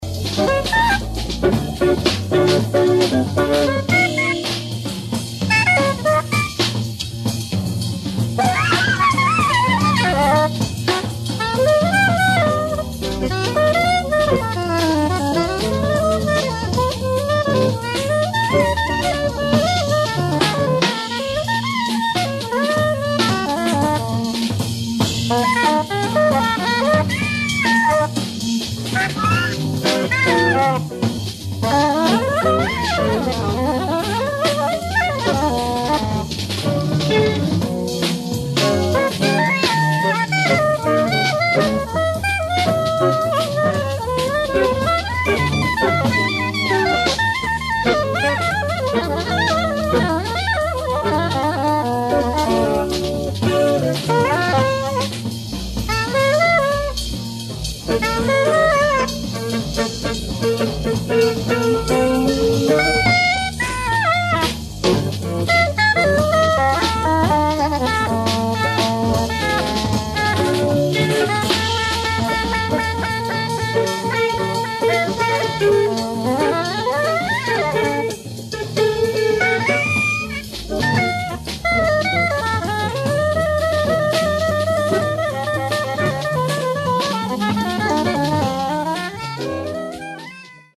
ライブ・アット・アリオスト劇場、レッジョ・ネレミリア、イタリア 03/26/1985
※試聴用に実際より音質を落としています。